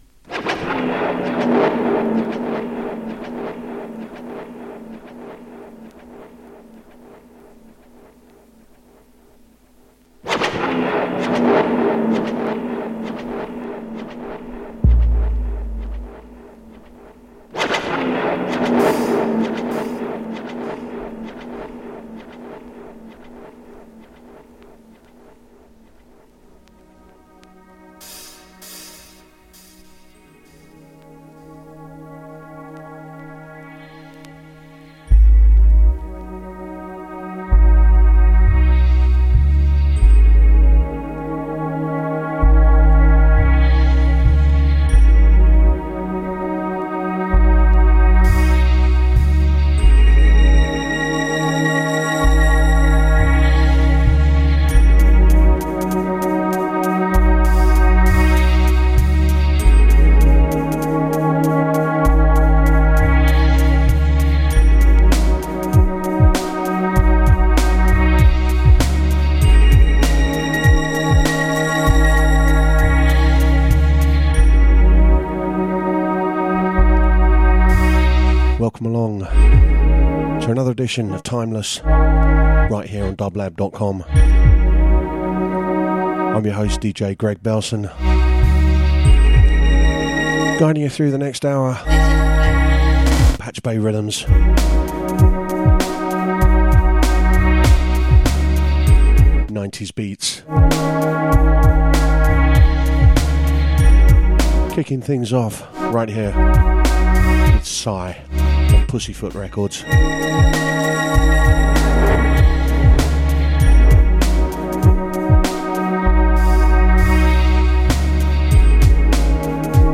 Electronic Synth